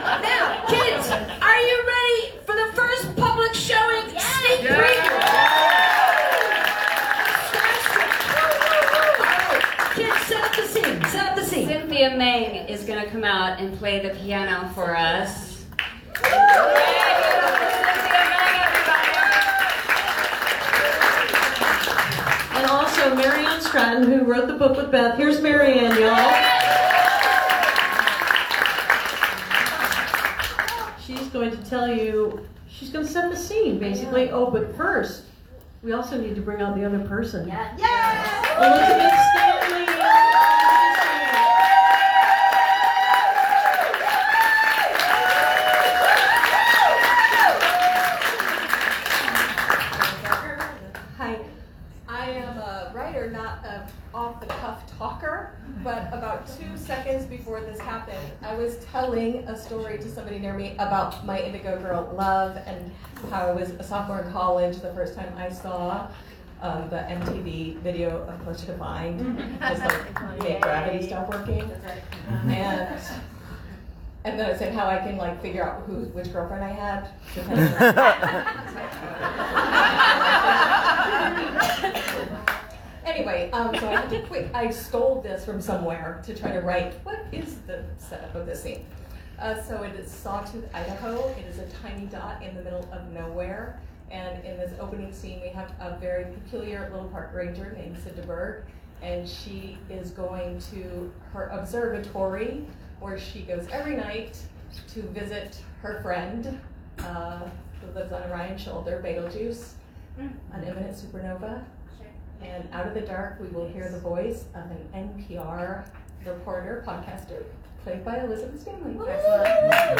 lifeblood: bootlegs: 2024-05-24: out of the box theatrics - new york, new york (emily saliers)
03. talking with the crowd (2:19)